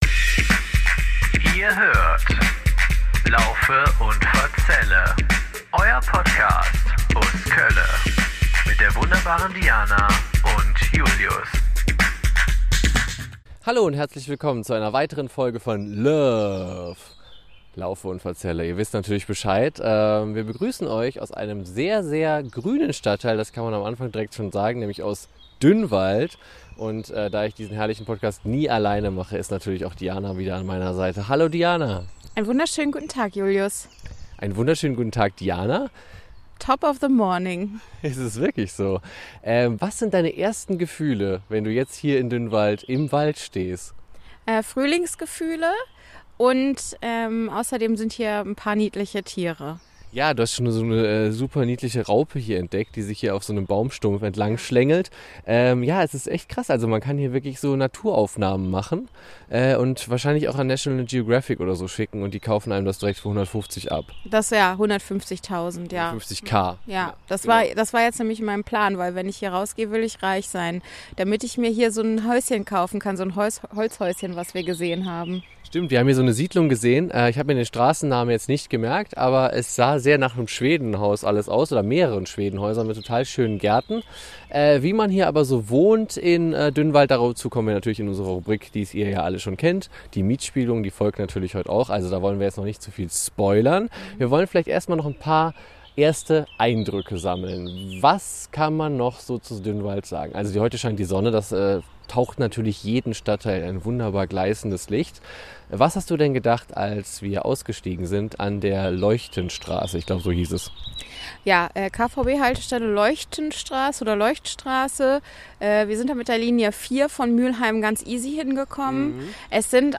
Auf einer wunderbaren Waldlichtung berichten wir über allerlei Interessantes aus Dünnwald.